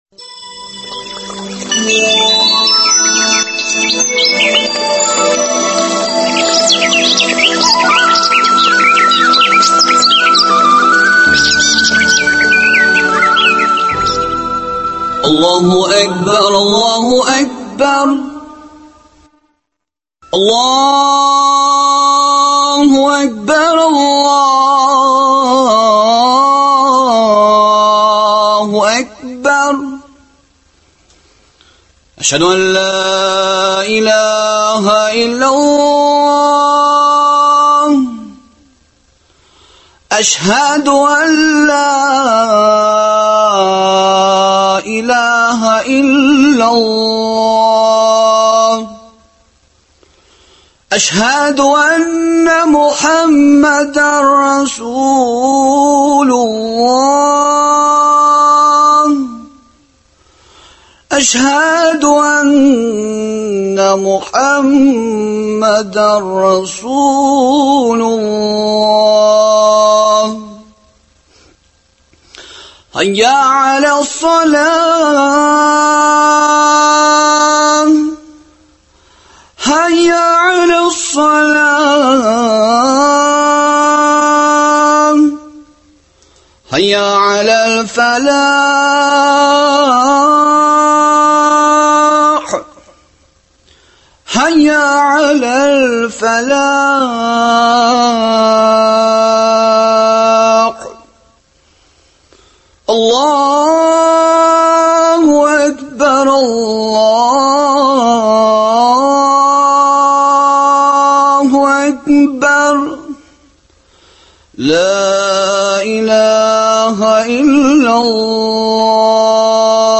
Бүгенге тапшыру аның тормыш юлына, әдәби һәм дини эшчәнлегенә багышлап әзерләнде. «Әдәп вә әхлак» сәхифәсендә Ризаэтдин Фәхретдиннең «Тәрбияле бала» хезмәтеннән өзек ишетә аласыз.